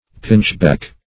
pinchbeck - definition of pinchbeck - synonyms, pronunciation, spelling from Free Dictionary
Pinchbeck \Pinch"beck\, a.